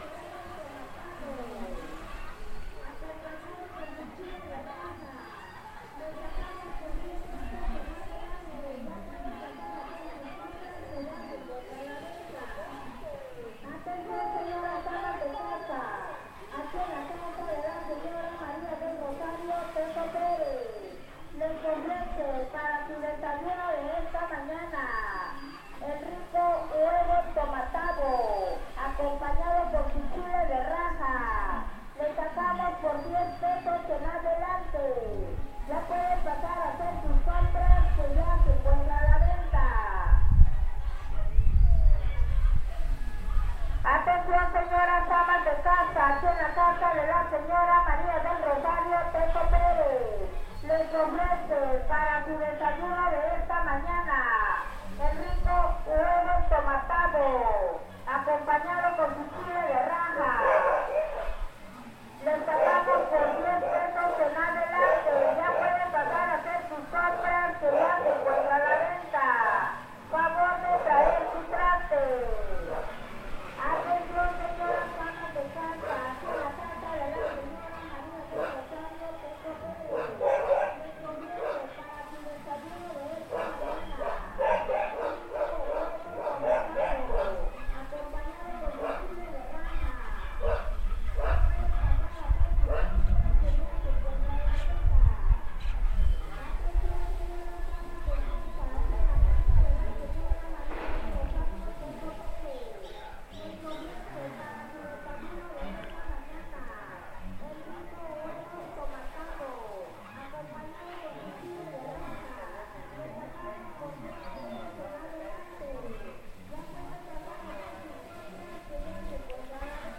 Anuncio: Huevo entomatado
Palo que habla o bocina para anuncios públicos en el pueblo, la mención tiene un costo de $30 pesos.
Grabación realizada el 29 de julio de 2021 a las 9:00 a.m. en el municipio de Suchiapa, Chiapas; México.
archivosonoro-palo-que-habla-huevo-entomatado.mp3